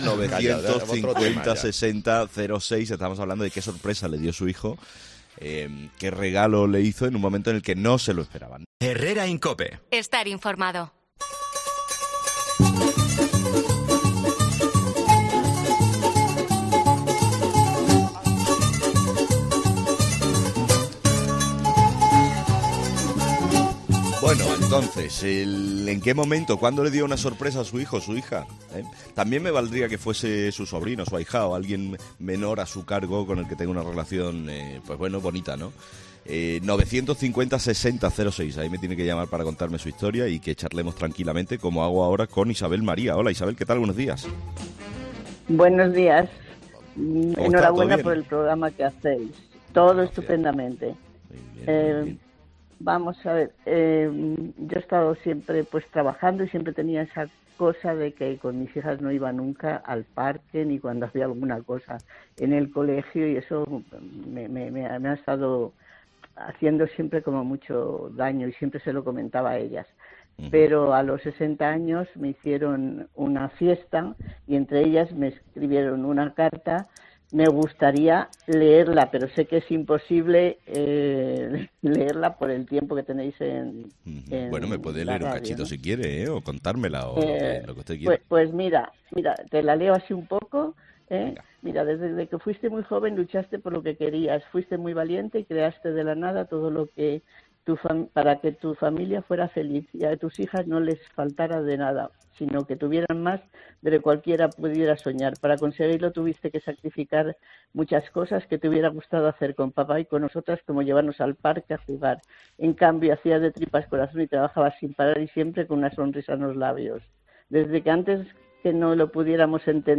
Es un testimonio que descubrimos en 'la hora de los fósforos'. Una sección en la que nuestros oyentes comparten anécdotas de todo tipo